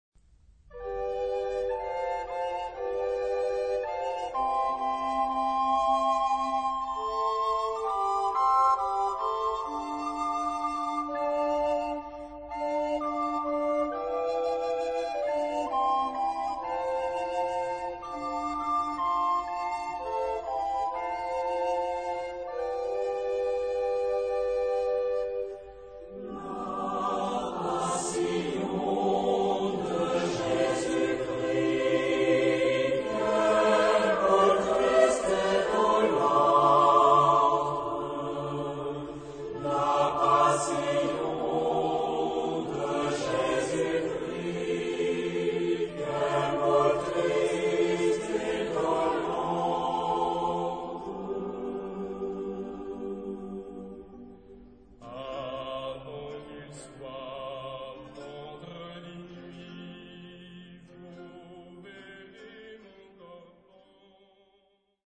Genre-Style-Forme : Populaire ; Chanson ; Profane ; Complainte
Caractère de la pièce : douloureux
Type de choeur : SATB  (4 voix mixtes )
Solistes : Basse (1)  (1 soliste(s))
Tonalité : sol mode de sol